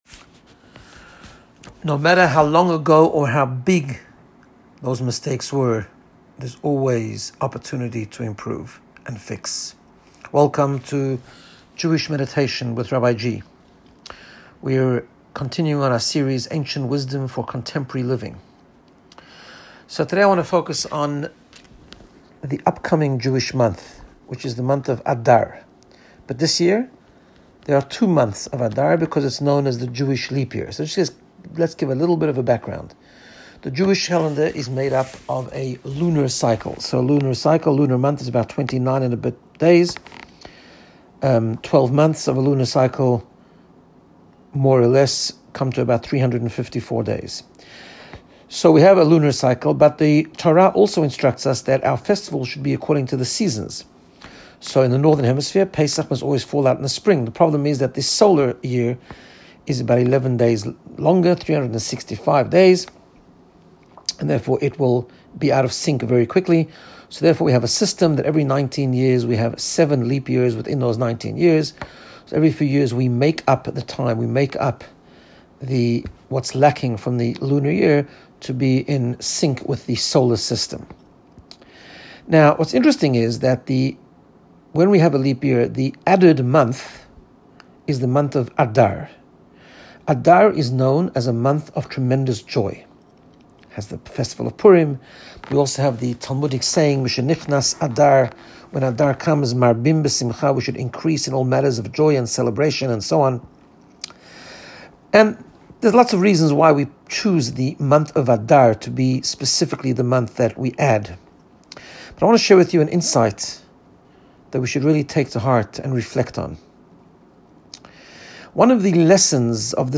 Guided Meditation to get you in the space of Personal Growth and Wellbeing. Ancient Ideas for Contemporary Living Part 2 In this episode we explore the lessons from a Jewish leap year! How we can always repair and rebuild.